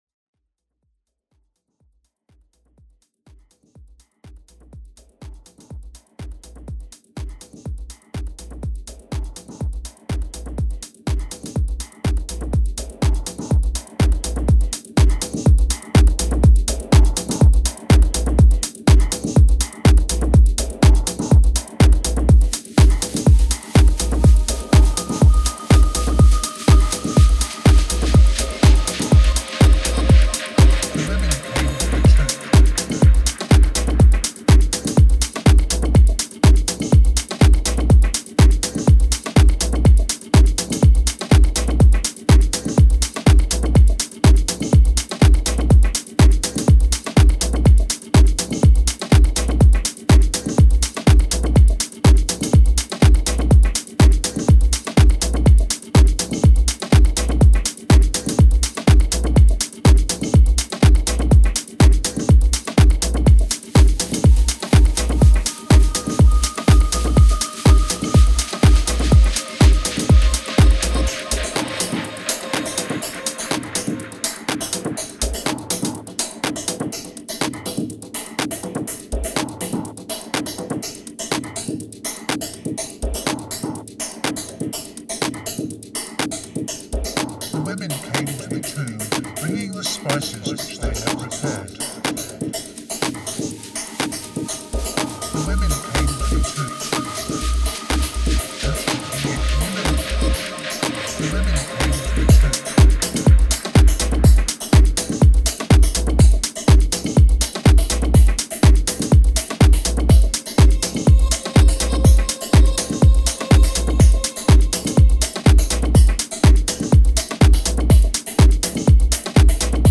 Four Groovy, Glittering Tracks
Style: Techno / Minimal